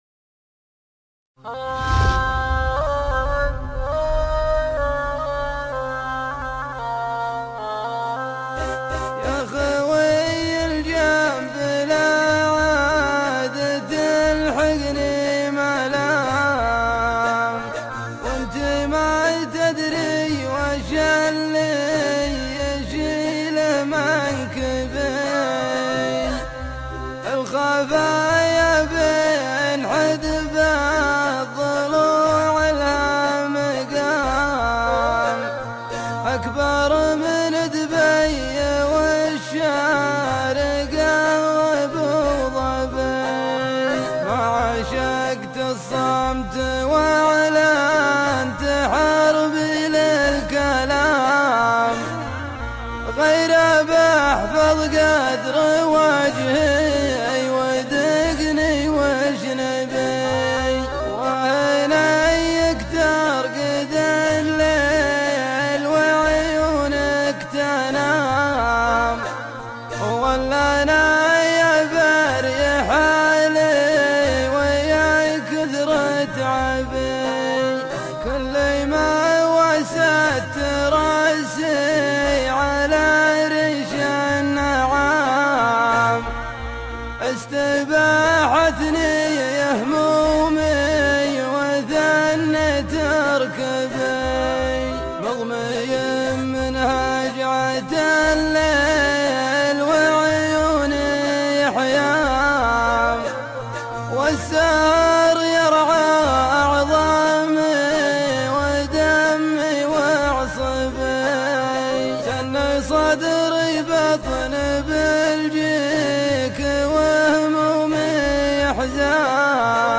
خوي الجنب - شيلة